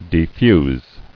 [de·fuse]